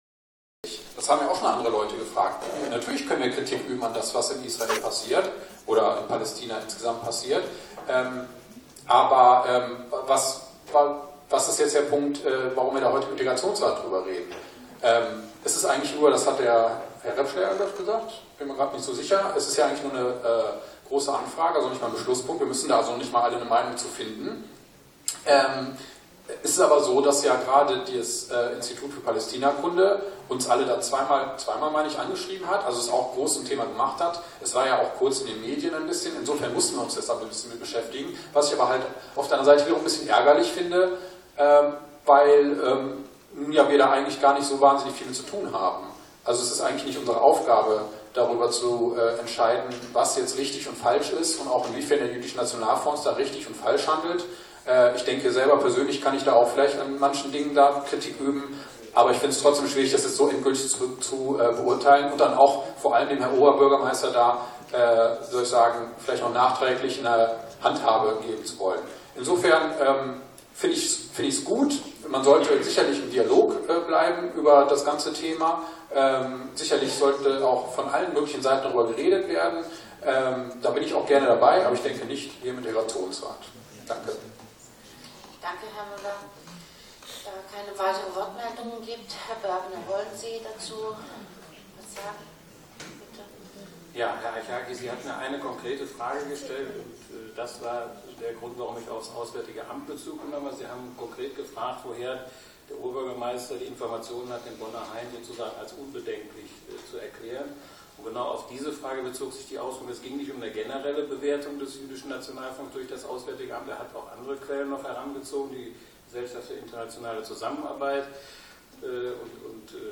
Teil II der Sitzung des Integrationsrats (3:56 min: audio)